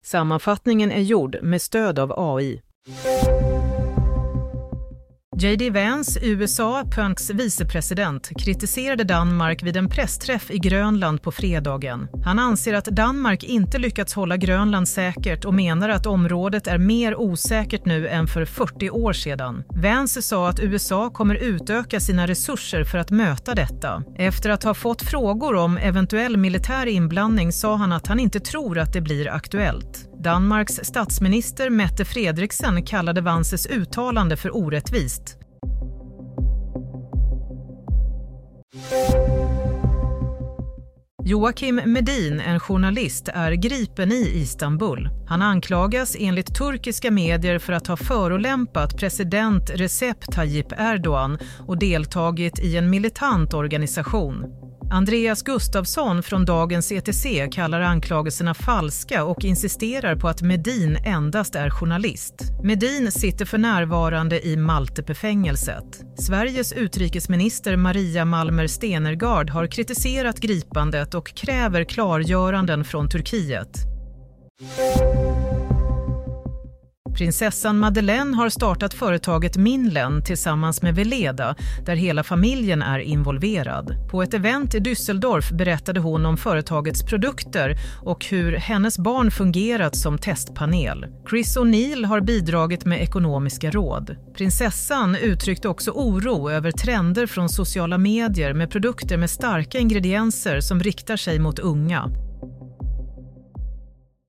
Nyhetssammanfattning – 28 mars 23.00
Sammanfattningen av följande nyheter är gjord med stöd av AI.